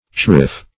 cherif - definition of cherif - synonyms, pronunciation, spelling from Free Dictionary Search Result for " cherif" : The Collaborative International Dictionary of English v.0.48: Cherif \Cher"if\ (sh[e^]r"[i^]f), n. See Sherif .